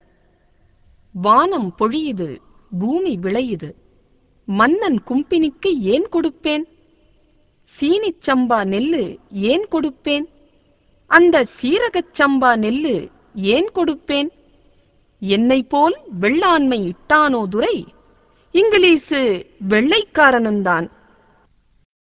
என்று நாட்டுப்புறப் பாடலில் கட்டபொம்மனின் வீரத்தை மக்கள் பாடுகின்றனர்.